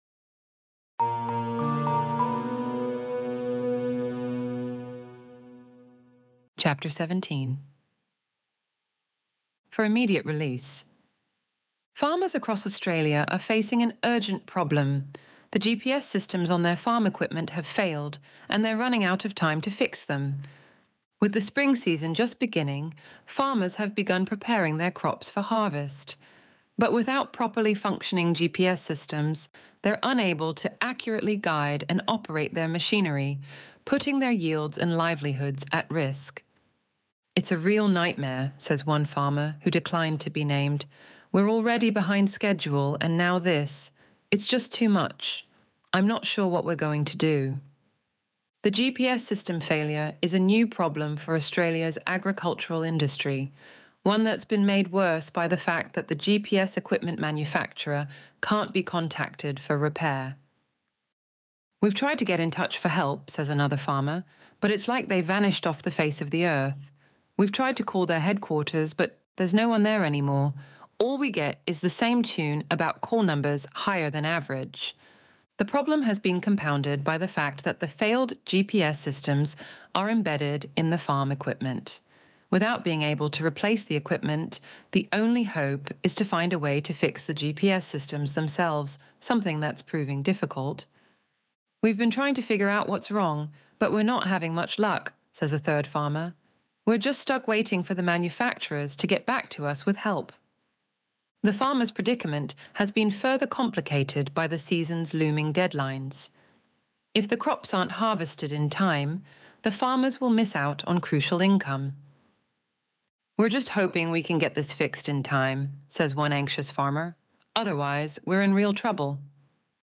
Extinction Event Audiobook